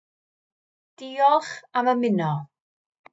Dee-olch am ymh-eeno